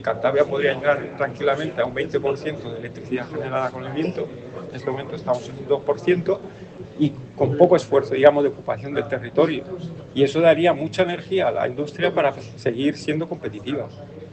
Jornada Energía en Cantabria, situación actual y renovables - CEOE-CEPYME